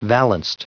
Prononciation du mot valanced en anglais (fichier audio)
Prononciation du mot : valanced